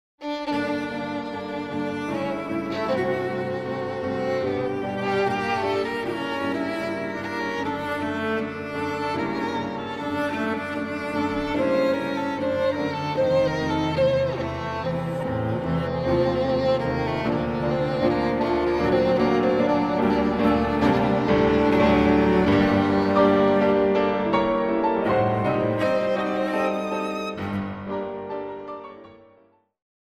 Piano Trio in G minor